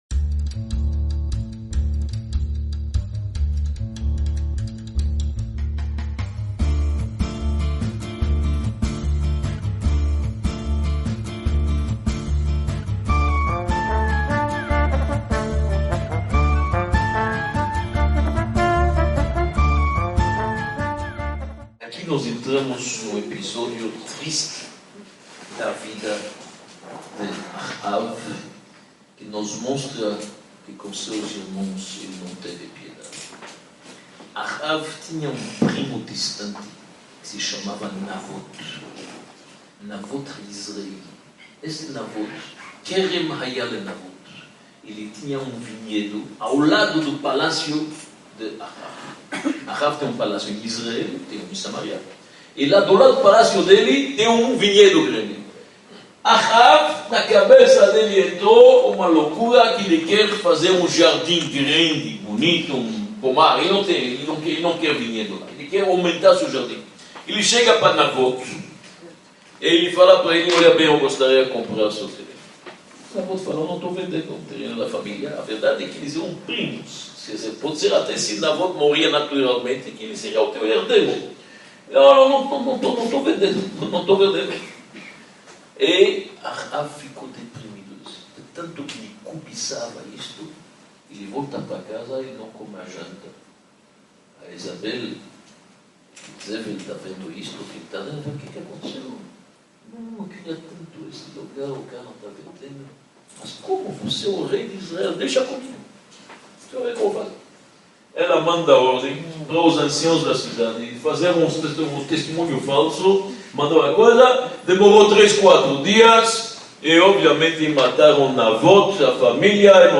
08 – A luta do profeta Elias; os milagres de Eliseu | Módulo I – Aula H | A História Desconhecida do Povo Judeu